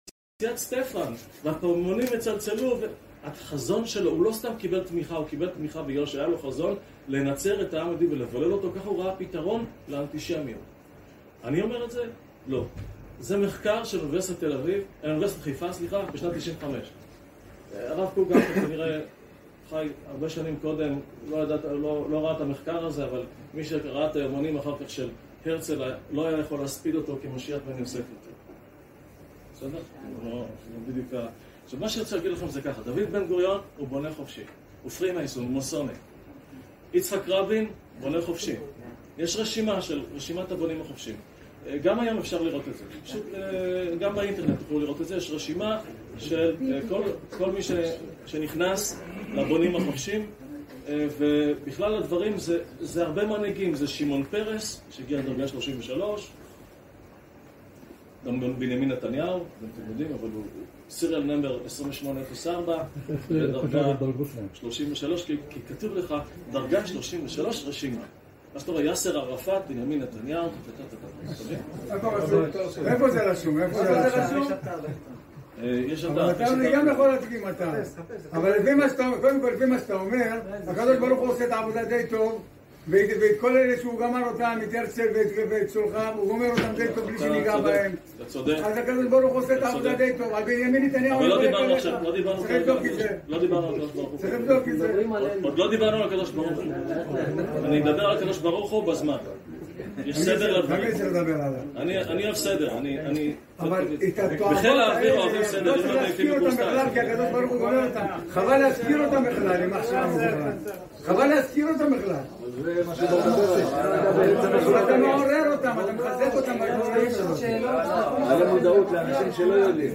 המשך ההרצאה בחיפה 2